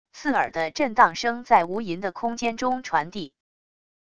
刺耳的震荡声在无垠的空间中传递wav音频